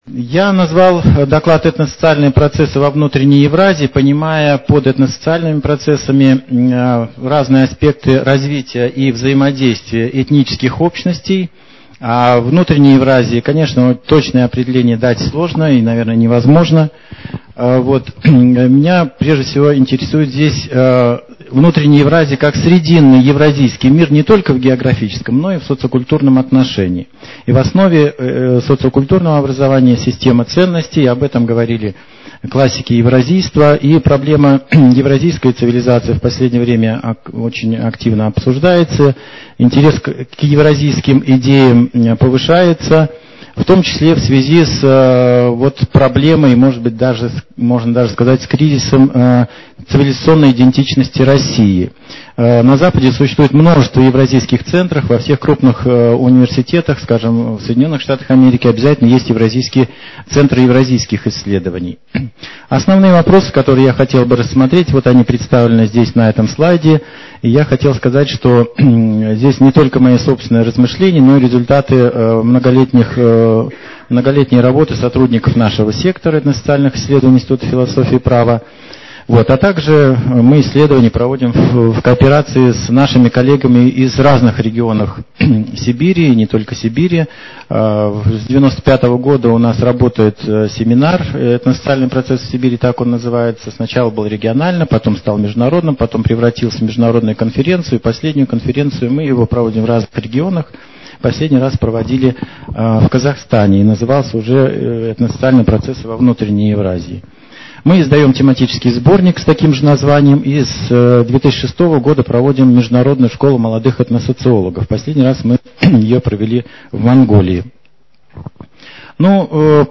Этносоциальные процессы во Внутренней Евразии (аудио) | Управление по пропаганде и популяризации научных достижений Сибирского отделения Российской академии наук